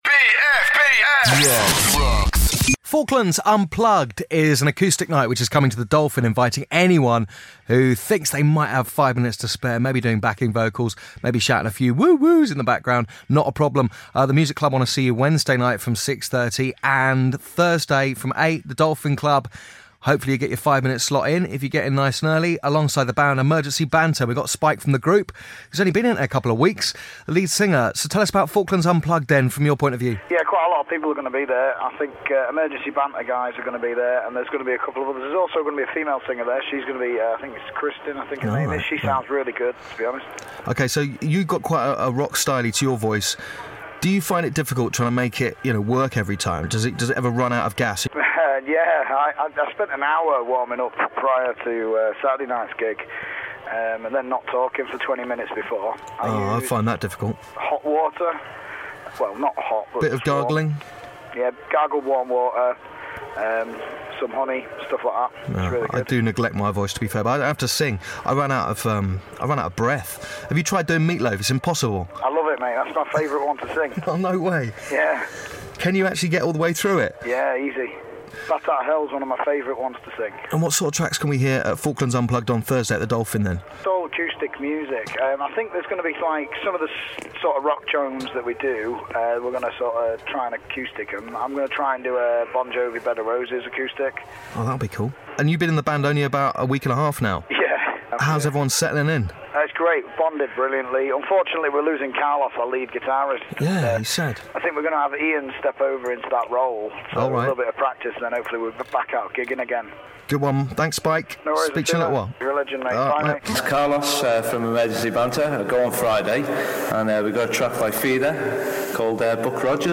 Falklands Forces Band Play Live On BFBS Rocks